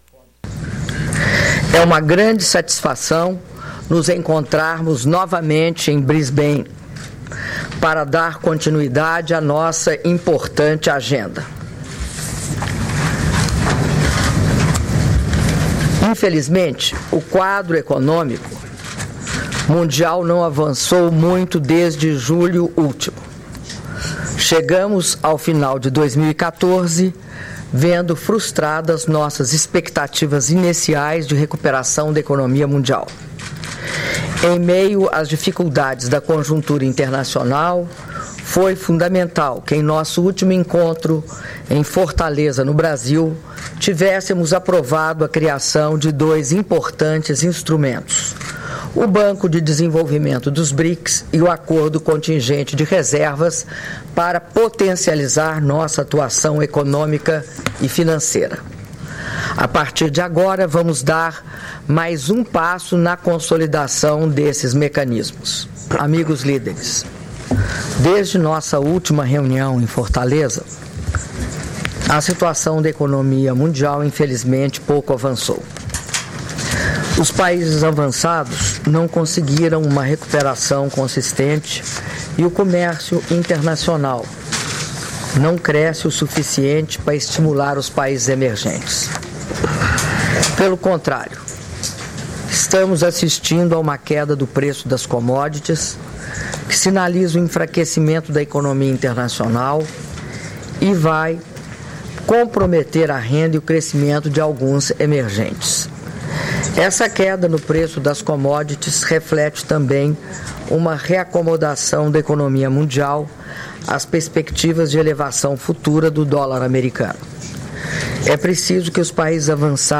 Áudio da declaração da Presidenta da República, Dilma Rousseff, durante a abertura da reunião com Chefes de Estado do BRICS